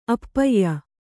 ♪ appayya